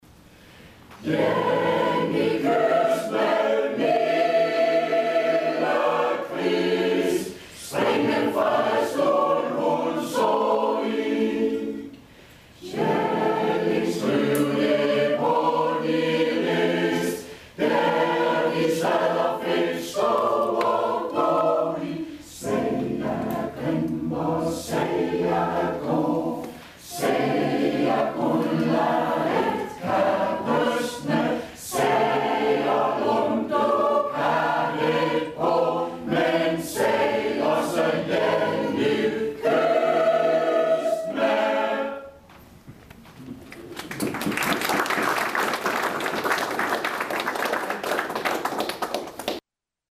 Korværker